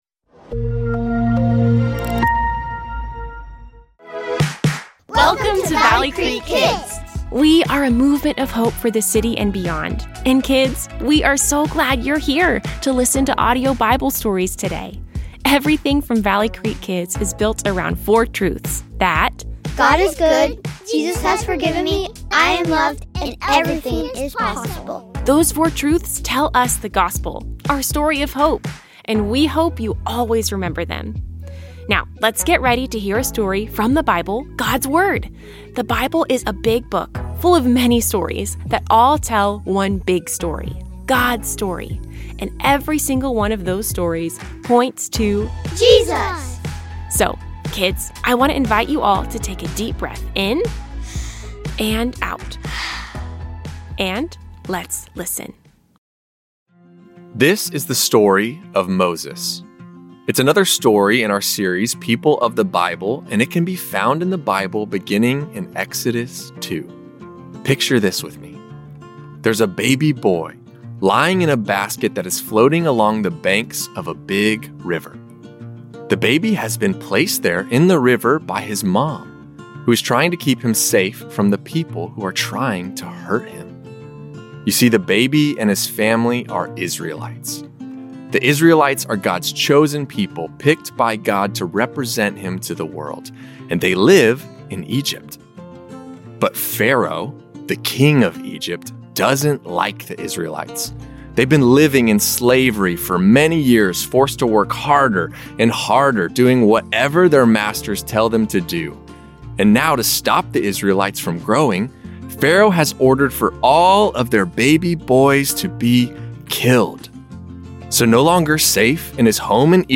Kids Audio Stories | People of the Bible | Moses Kids Audio Bible Stories Share June 23, 2025 Add to My List Join us as we discover the story of Moses! We'll see that God is with Moses on every step of his journey, and that the life of Moses points to the life of another who God will send to lead His people: Jesus.